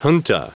Prononciation du mot junta en anglais (fichier audio)
Prononciation du mot : junta